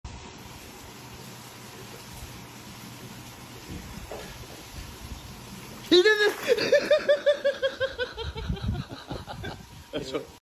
Bro laugh took me out sound effects free download